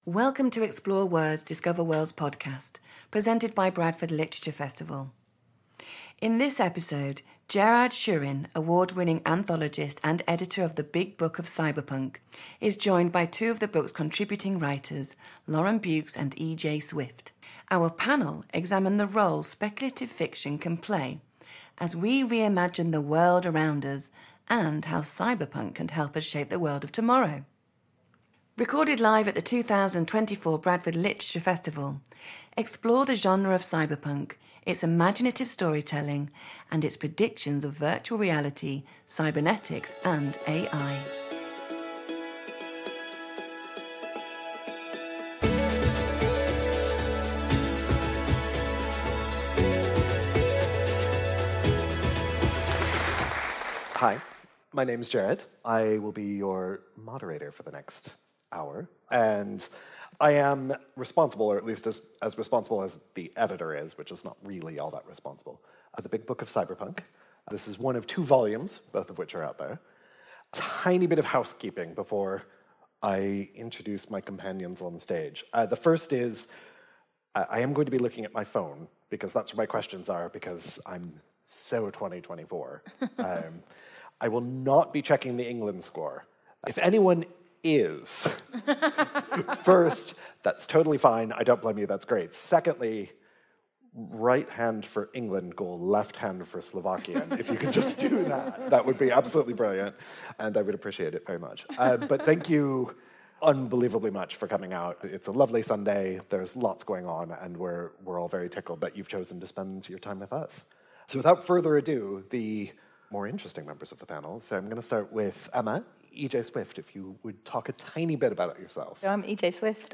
Join us for a lively discussion about the role speculative fiction can play as we reimagine the world around us and how cyberpunk can help us shape the world of tomorrow.